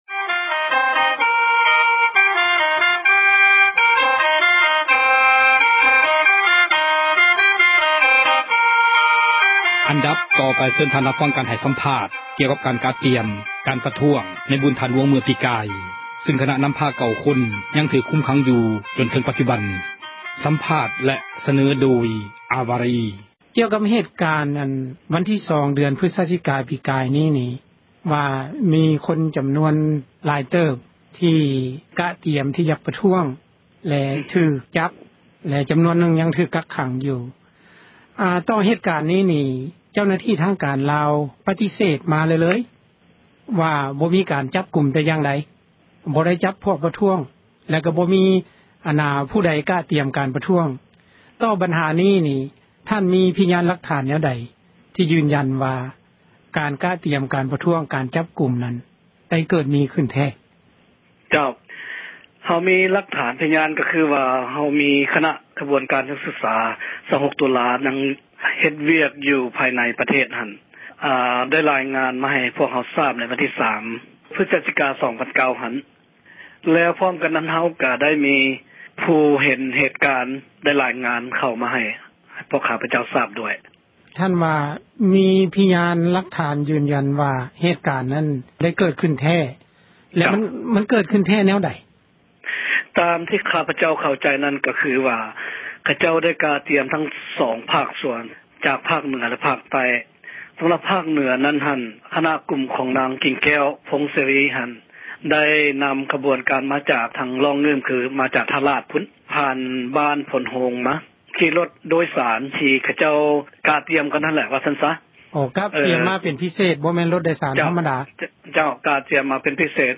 ການສໍາພາດເຖີງເຫດການ ຜູ້ນໍາ 9 ຄົນຖືກຈັບ